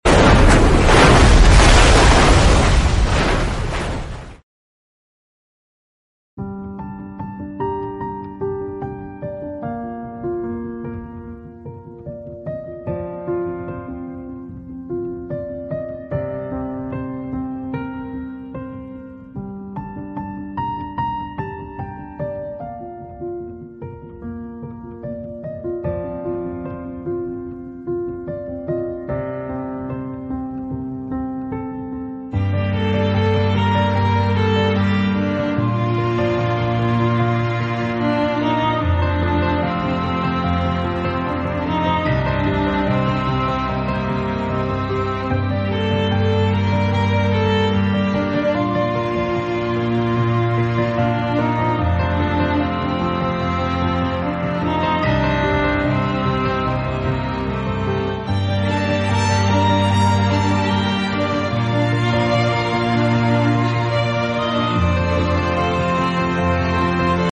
Lanhsa Flight 018 Possible Engine Sound Effects Free Download
Lanhsa Flight 018 Possible engine